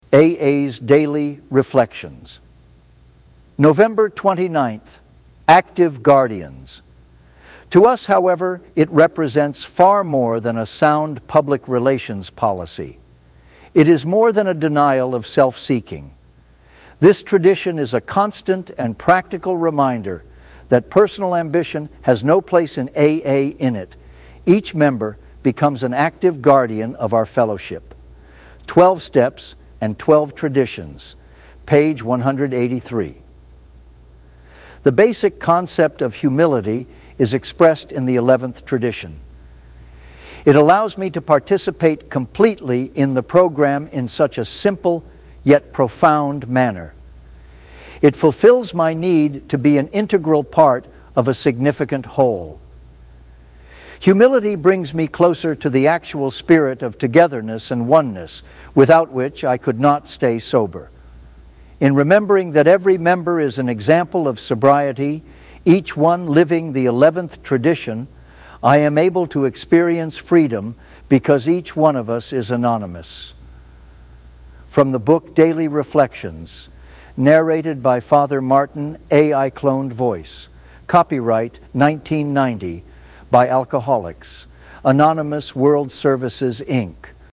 A.I. Cloned Voice.